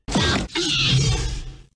Звуки раптора
Звук страдающего дромеозаврида